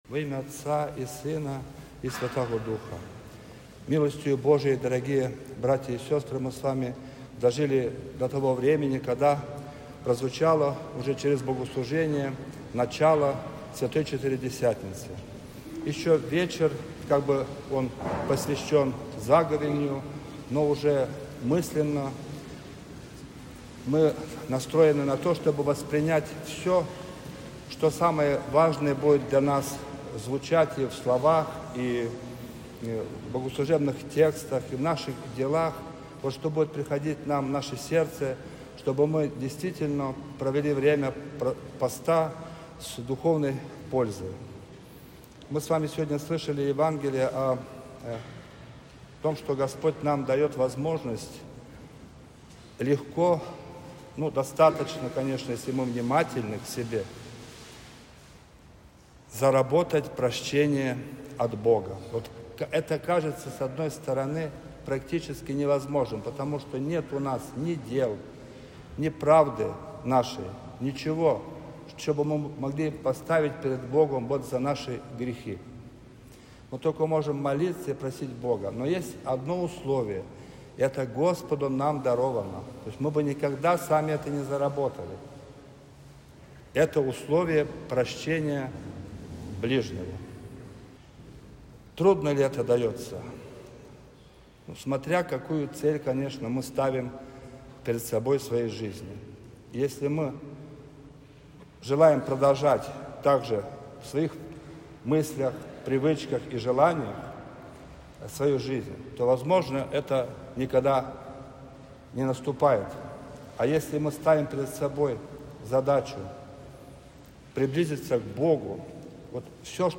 22 февраля 2026 года, в канун седмицы 1-й Великого поста, в храме Всемилостивого Спаса была совершена вечерня и прочитаны молитвы на начало Святой Четыредесятницы.
Проповедь